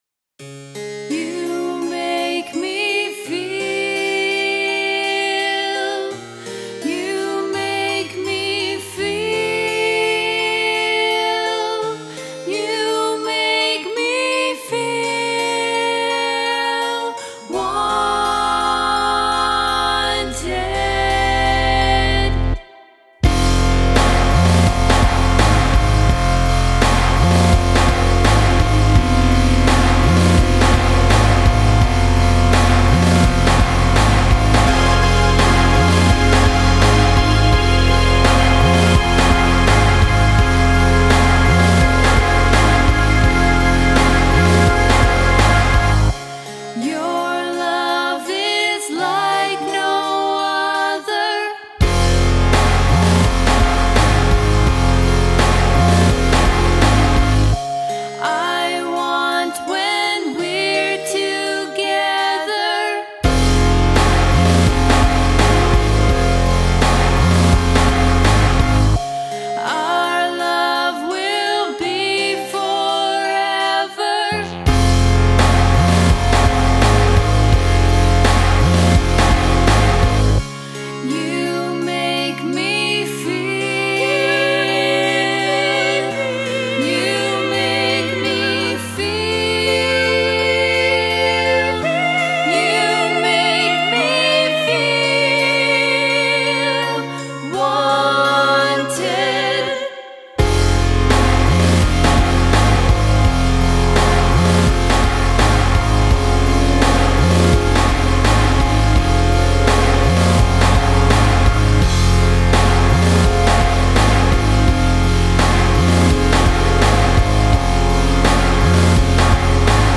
Vocals
Soprano